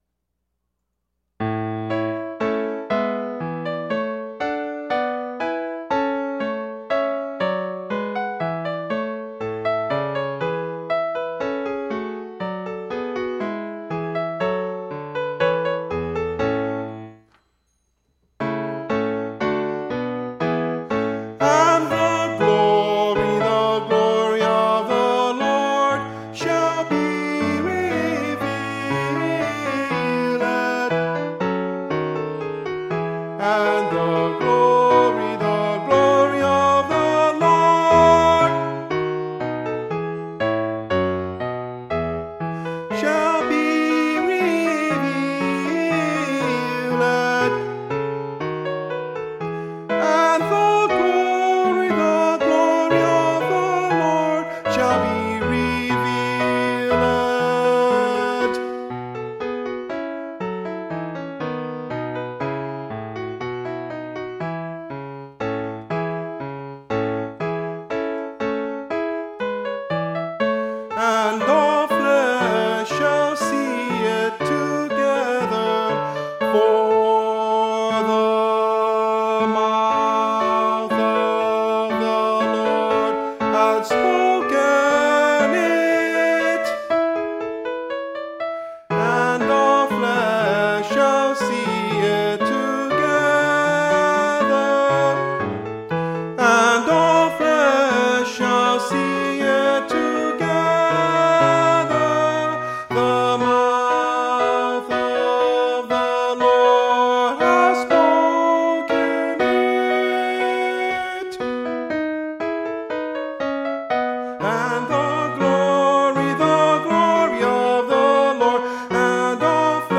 For Everybody else; Key of A (Original):
Tenor   Instrumental | Downloadable   Voice | Downloadable